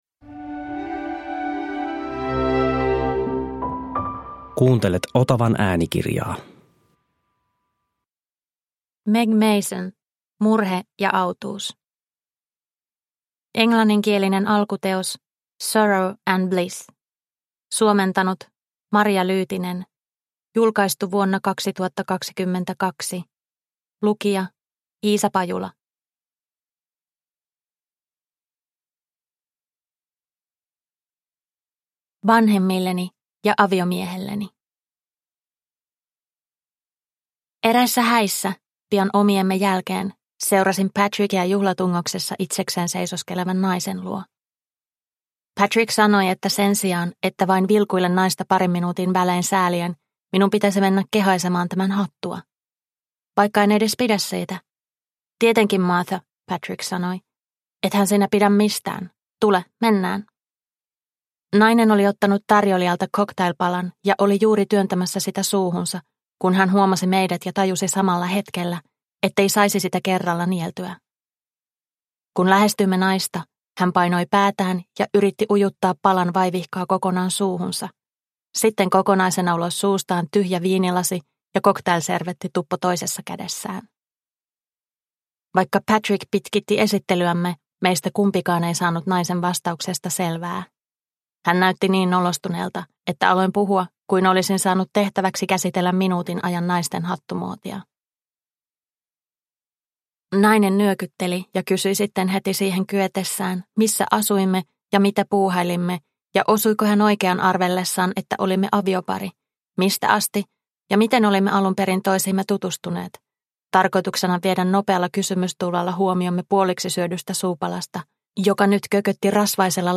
Murhe ja autuus – Ljudbok – Laddas ner